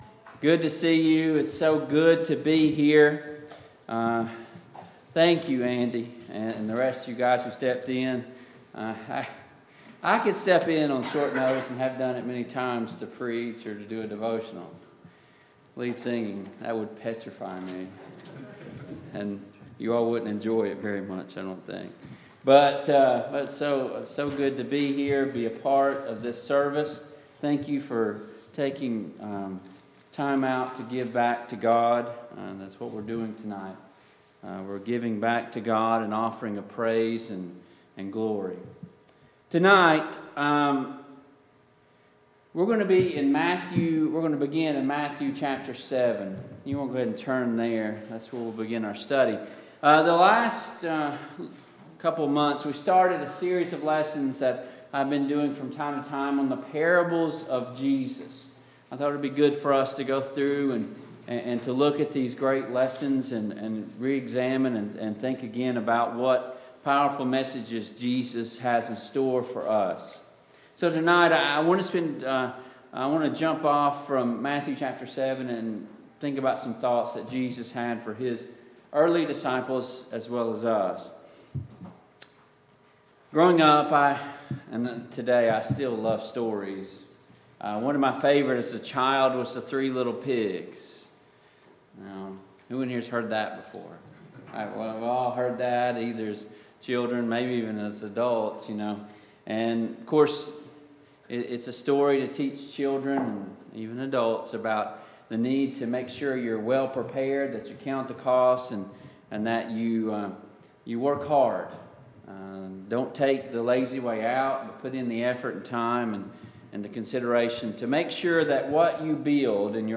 Service Type: PM Worship